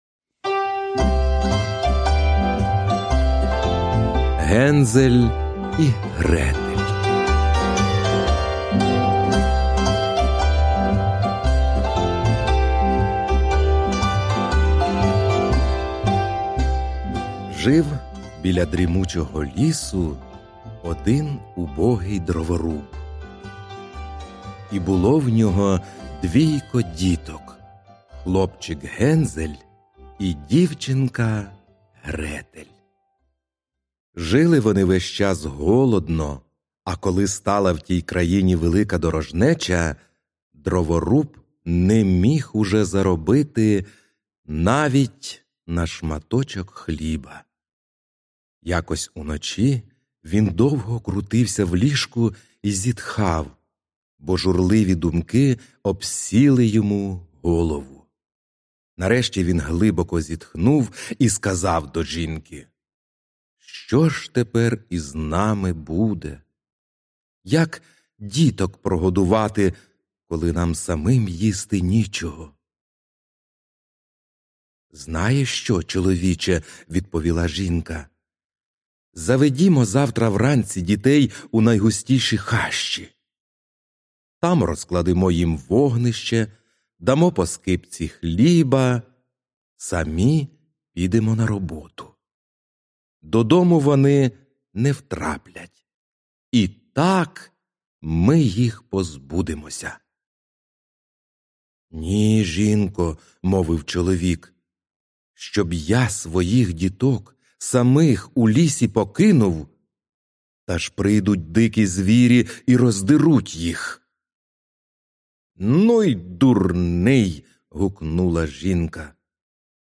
Аудіоказка Гензель і Гретель